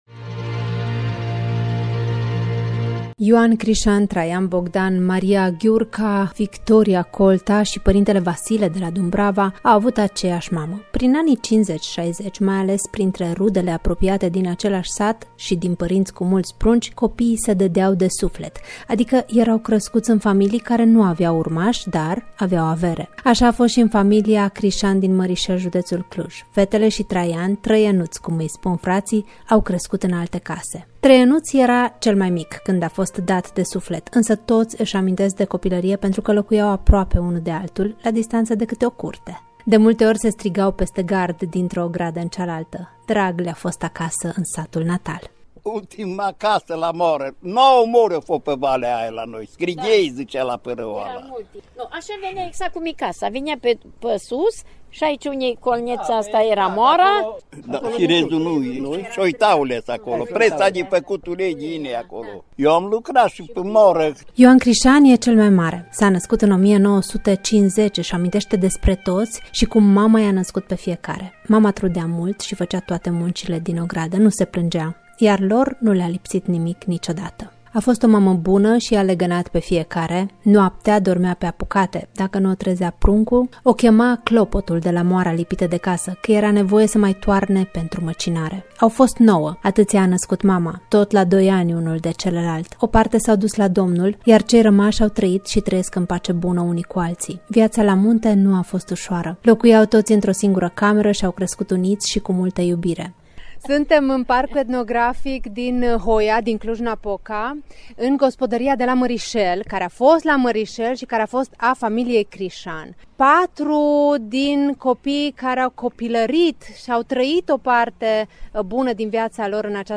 reportaj-gospodaria-marisel-1.mp3